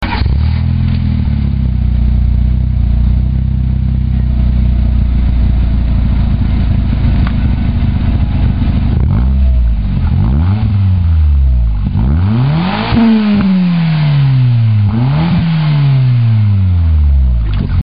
若干音質が劣化してますがご勘弁を（；−−）ﾉ
レベル１が静かな方で、逆にレベル５が爆音です。
登録No． パーツの種類 マフラー
音量 レベル３
・インナーサイレンサー無し。